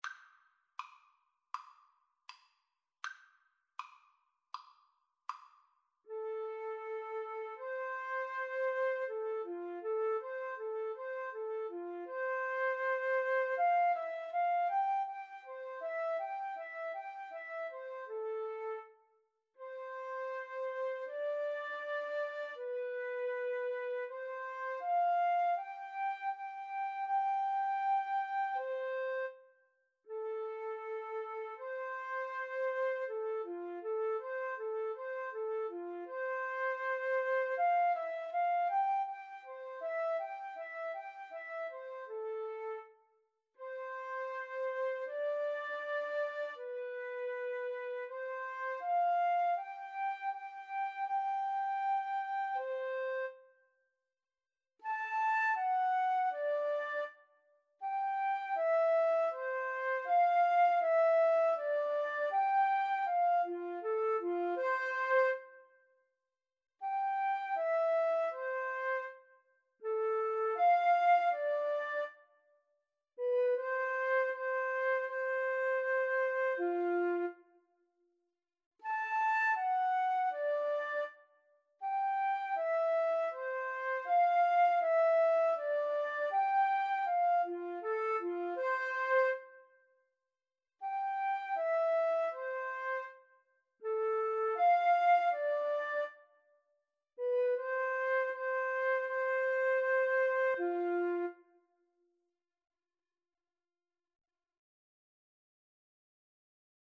Flute Duet  (View more Easy Flute Duet Music)
Classical (View more Classical Flute Duet Music)